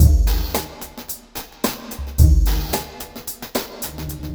RemixedDrums_110BPM_48.wav